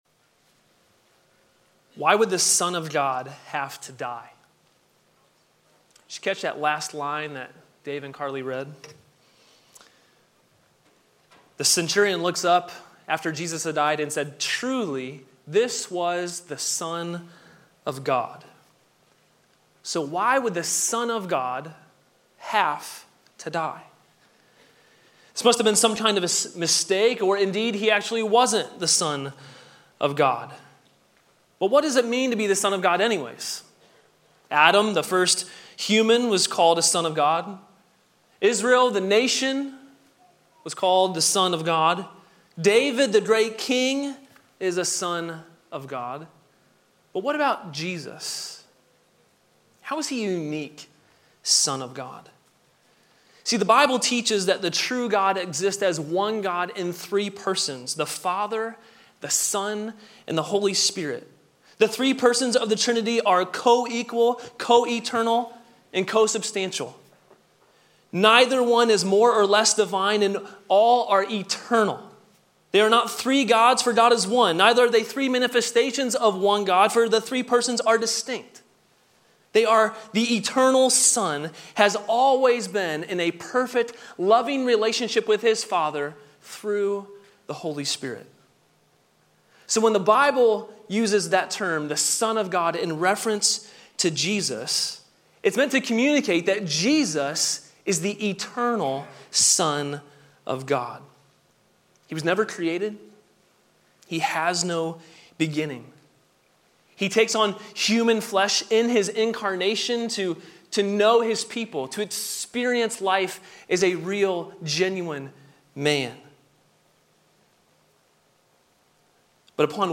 Sermon: "All Glory be to Christ!" from Guest Speaker • Grace Polaris Church